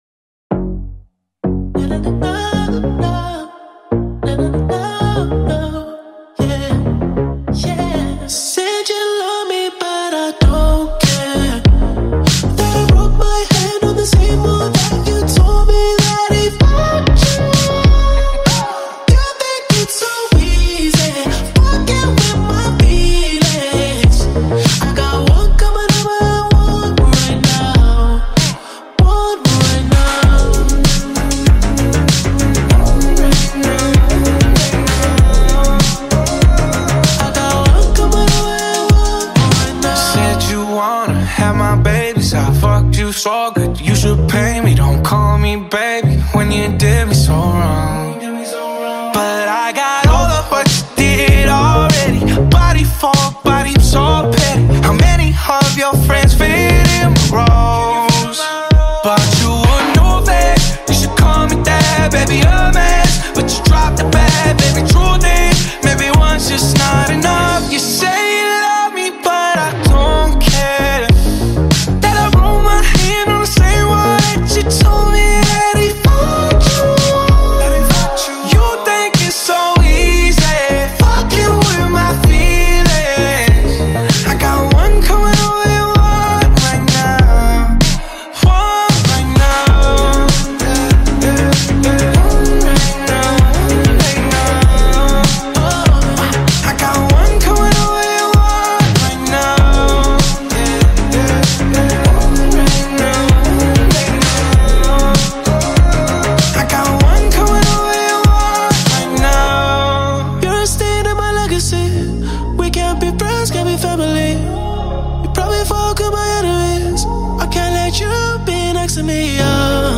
R&B/Soul